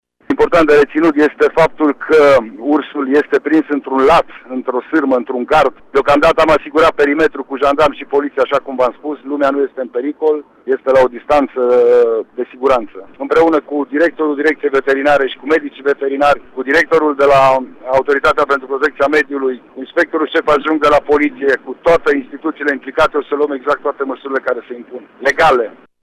Prefectul Lucian Goga a declarat pentru Radio Tg.Mureș că perimetrul a fost asigurat, iar localnicii sunt în afara oricărui pericol: